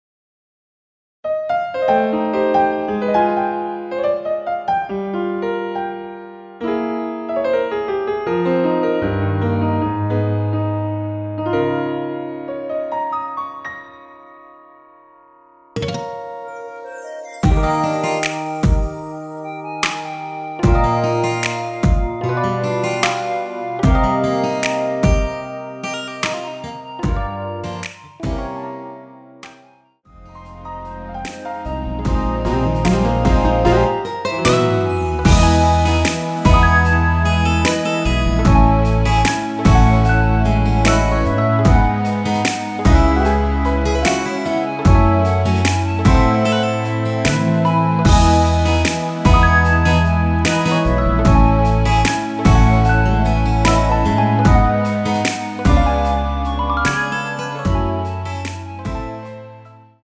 원키 멜로디 포함된 MR입니다.
Eb
앞부분30초, 뒷부분30초씩 편집해서 올려 드리고 있습니다.
중간에 음이 끈어지고 다시 나오는 이유는